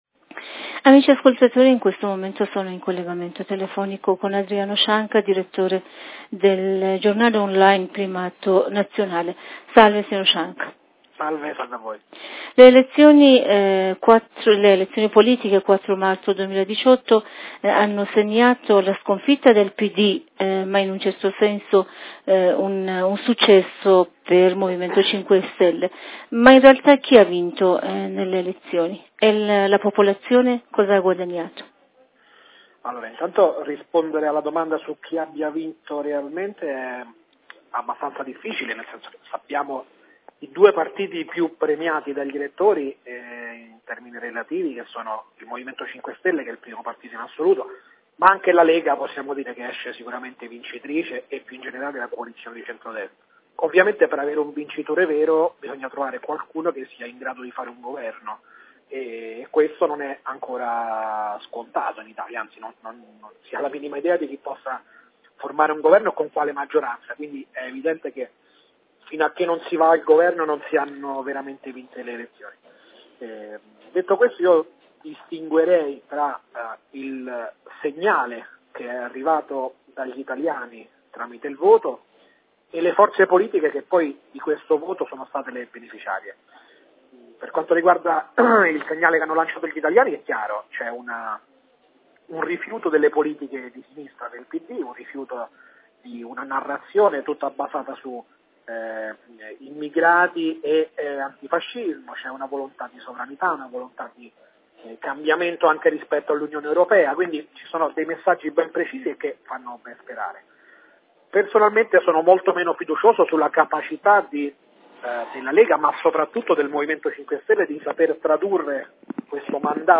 e' stato intervistato dalla nostra Redazione